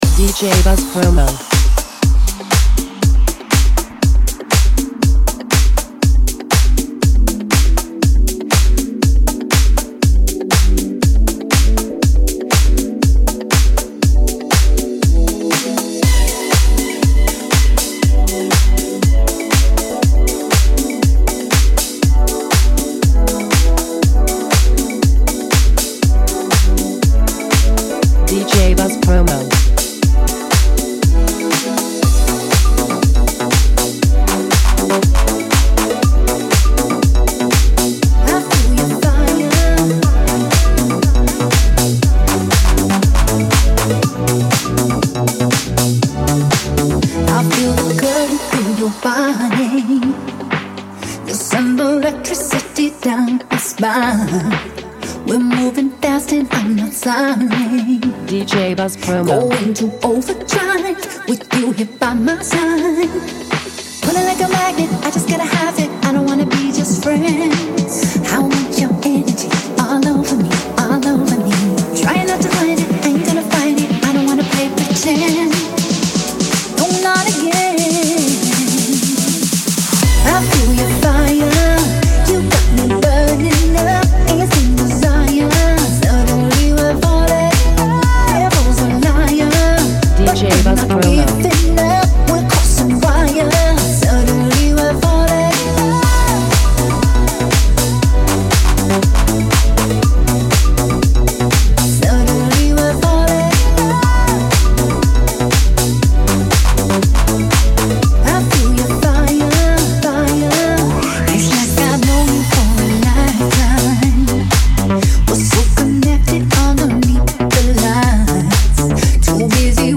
New Remixes!
pure Dance Music banger